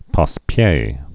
(päs-pyā)